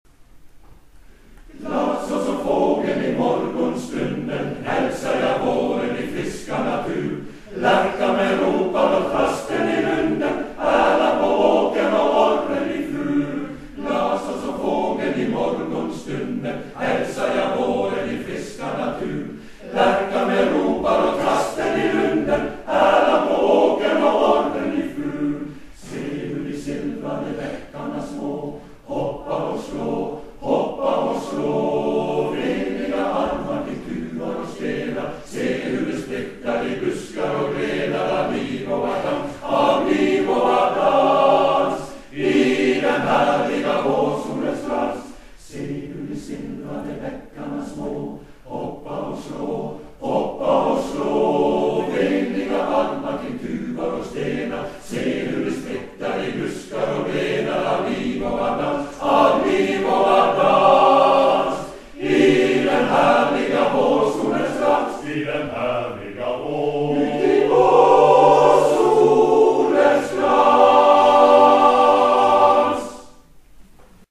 NK Hordaland Sangerstevnet   Garnes / Indre Arna lørdag 6.juni på Garnes Ungdomskole
En profankonsert på Garnes Ung.skole kl 1430 og konsert i Arna Kirke kl 1800.
Opptak fra Garnes Ung.skole: